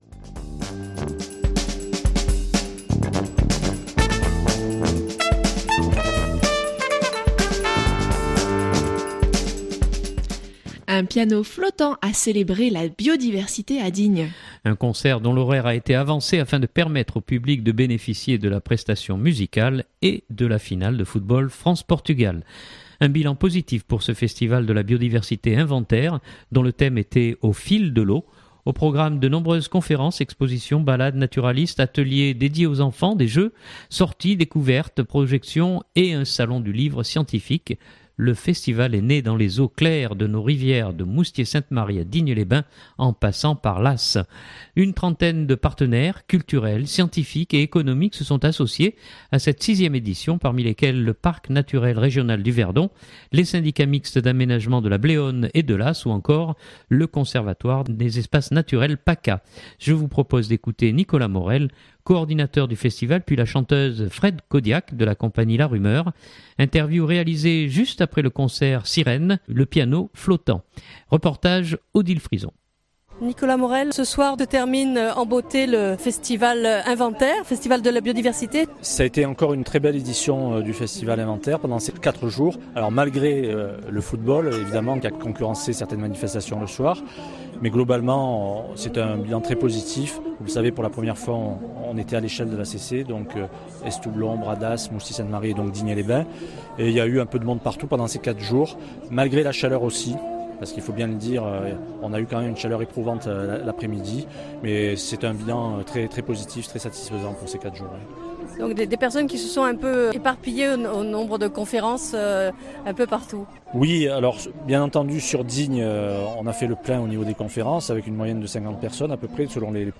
Interview réalisée juste après le concert Sirène, le piano flottant.